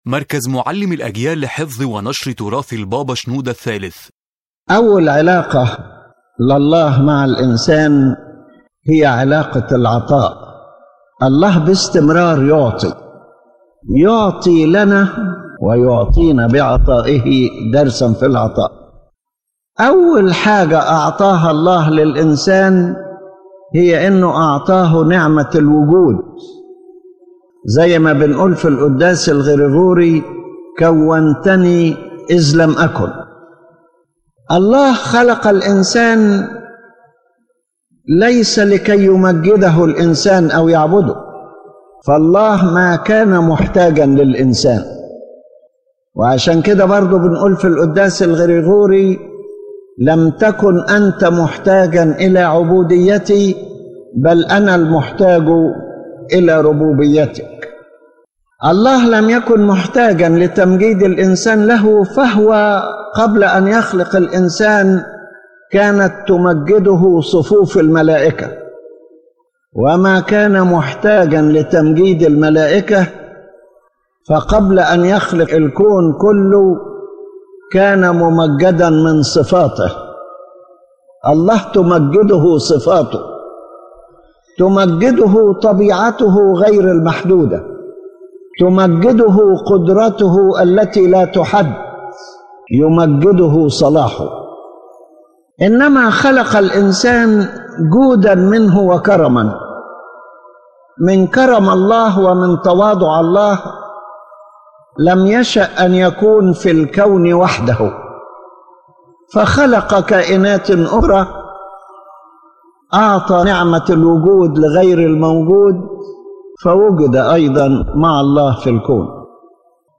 The lecture explains that the first relationship between God and man is a relationship of giving. God continually gives, not because He needs man, but out of His generosity, goodness, and divine humility.